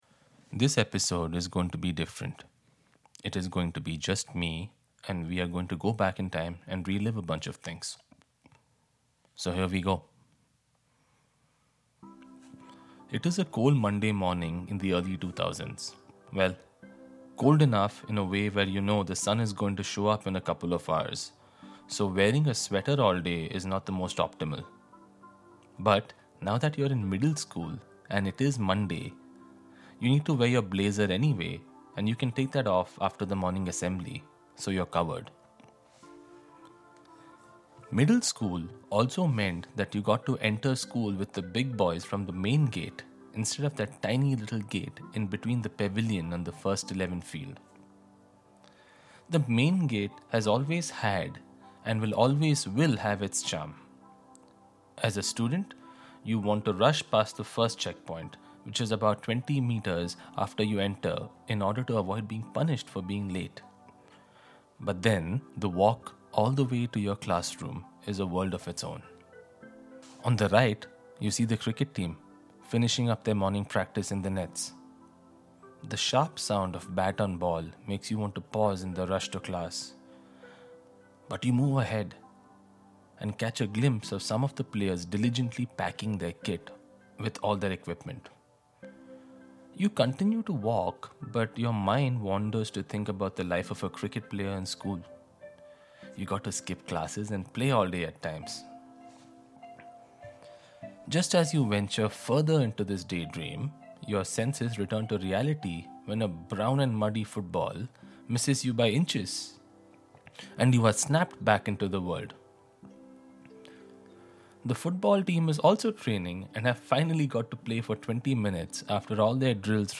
A "Special Class" solo episode where we go back and fondly remember school and the man who played a major role in making it great.